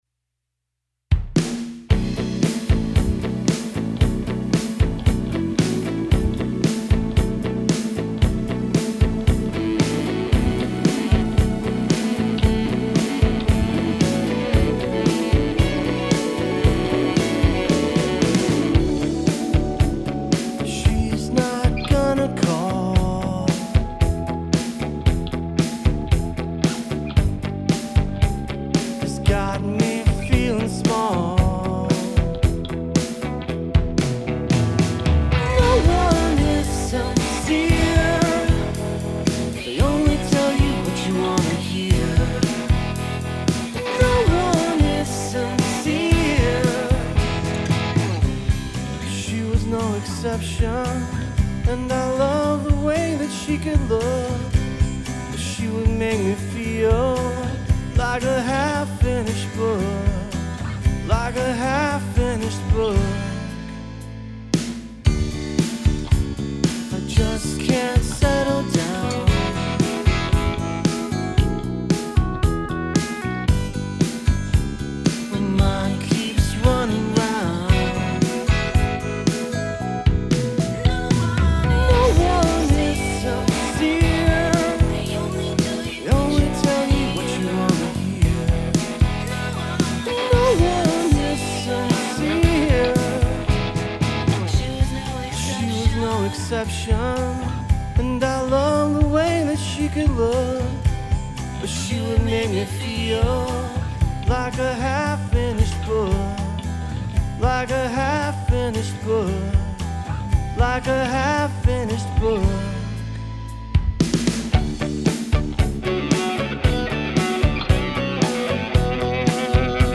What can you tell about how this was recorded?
Attached is the mix I've done so you can get an idea.